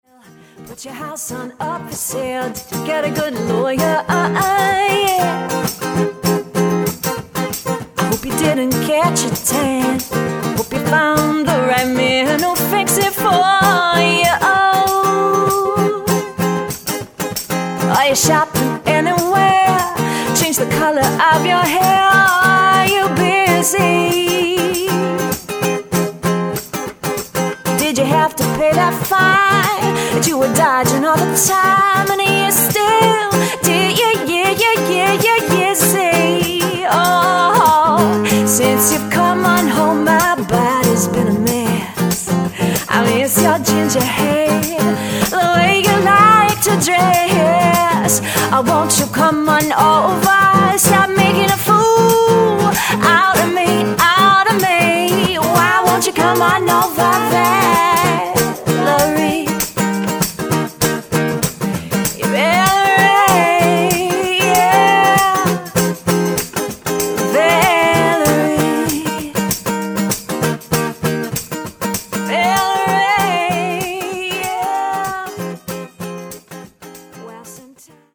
Live Demo’s!
guitar